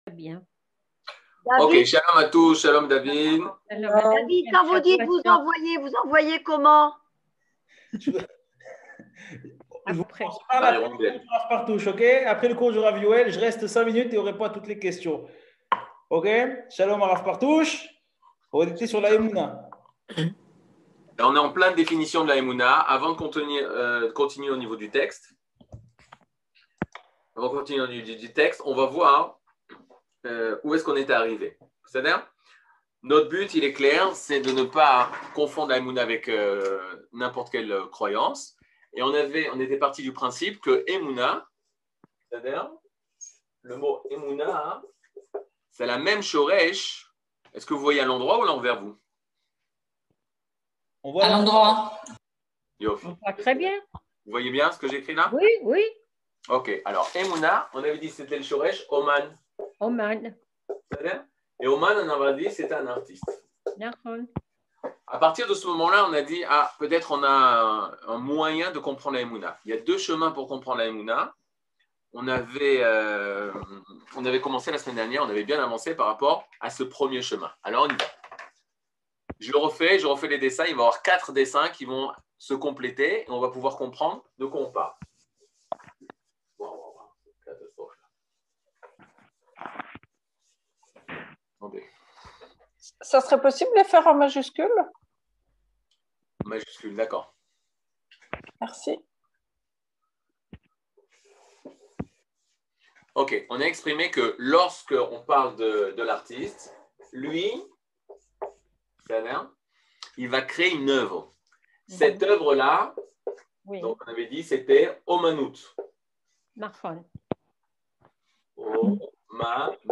Catégorie Le livre du Kuzari partie 17 00:56:23 Le livre du Kuzari partie 17 cours du 16 mai 2022 56MIN Télécharger AUDIO MP3 (51.61 Mo) Télécharger VIDEO MP4 (124.28 Mo) TAGS : Mini-cours Voir aussi ?